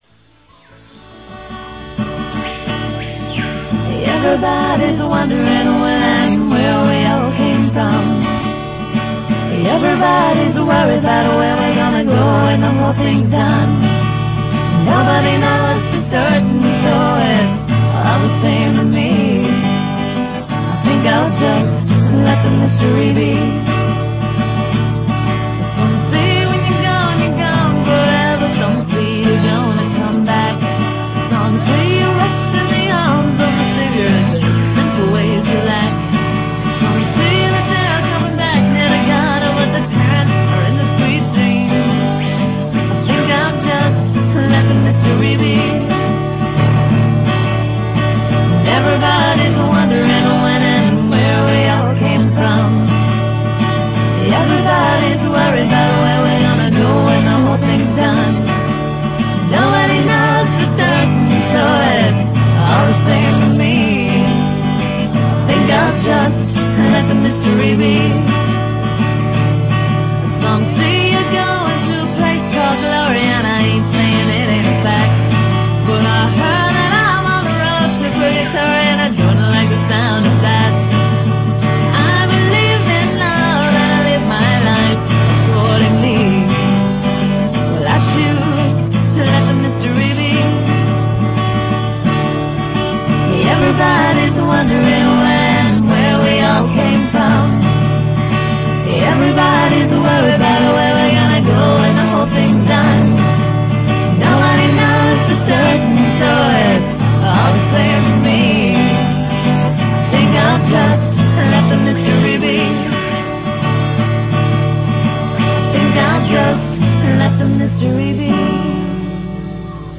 It was recorded in Logan Square.